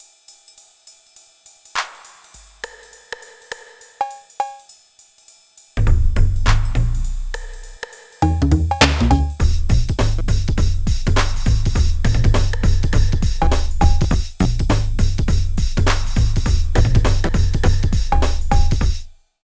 113 LOOP  -R.wav